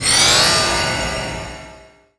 shield_03.wav